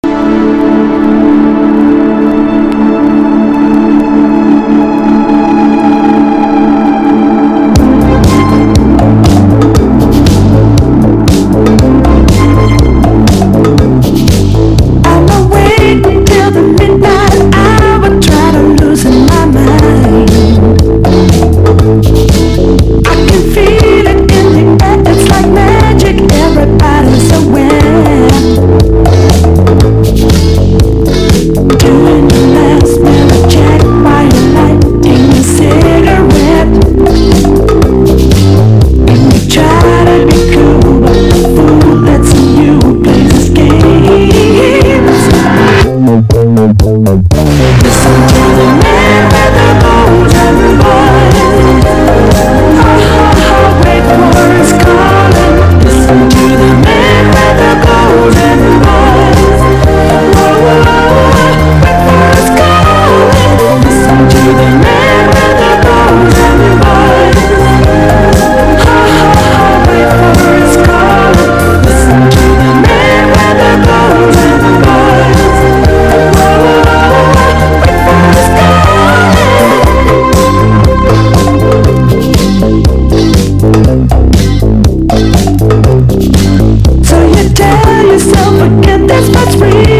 ポコポコ打ったトラックが楽しげでGOOD！
NEWWAVE DISCO